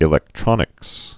(ĭ-lĕktrŏnĭks, ēlĕk-)